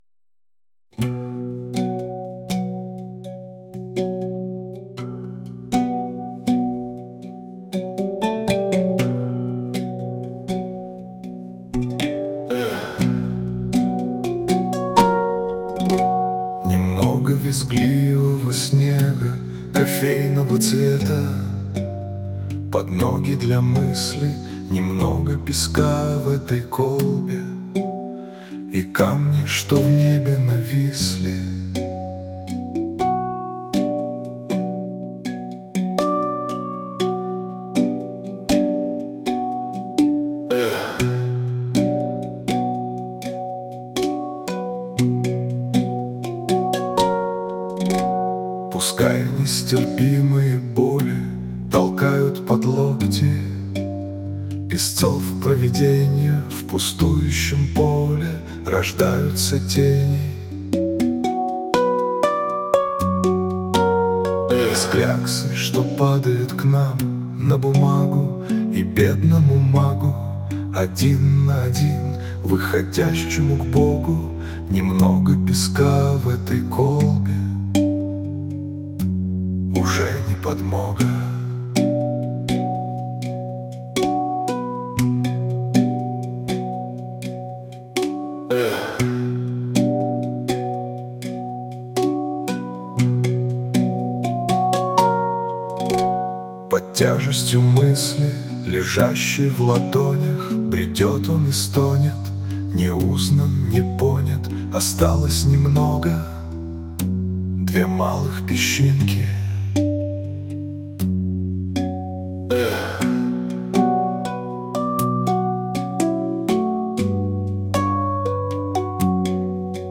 • Жанр: Фолк
Песня с голосом Павла Смеяна...